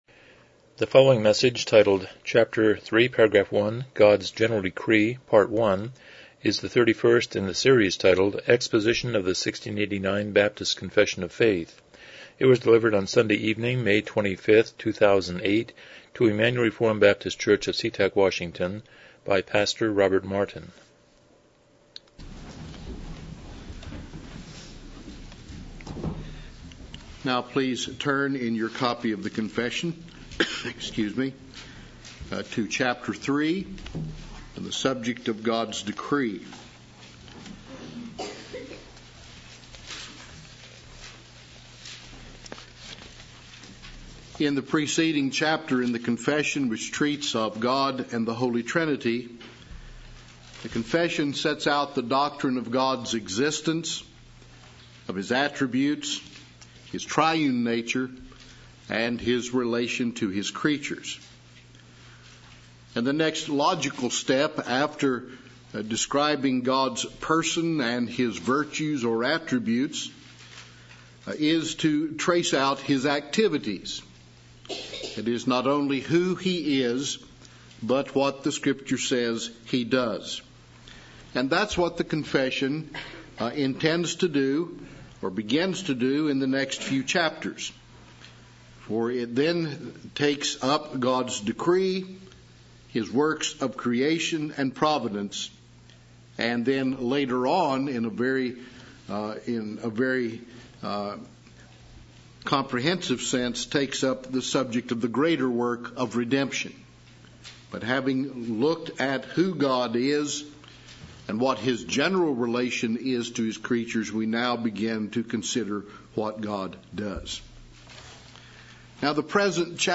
1689 Confession of Faith Service Type: Evening Worship « 46 Review #4